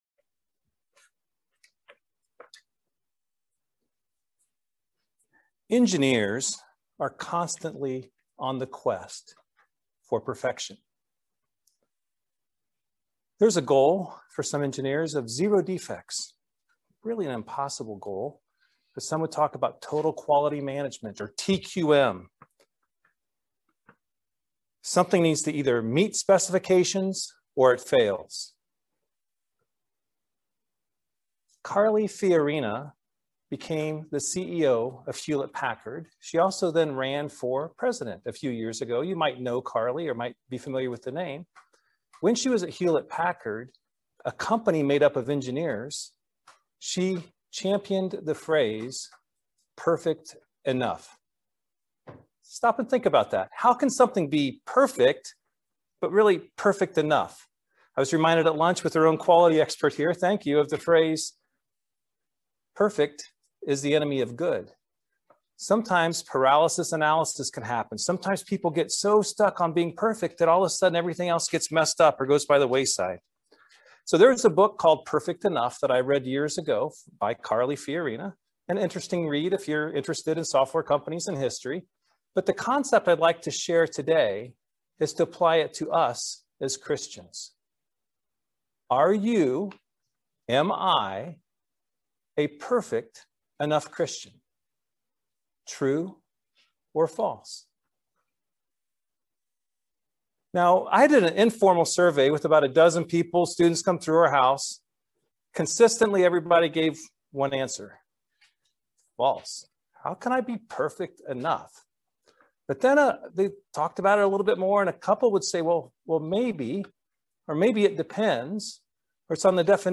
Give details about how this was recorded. Given in Lexington, KY